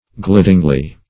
glidingly - definition of glidingly - synonyms, pronunciation, spelling from Free Dictionary Search Result for " glidingly" : The Collaborative International Dictionary of English v.0.48: Glidingly \Glid"ing*ly\, adv.
glidingly.mp3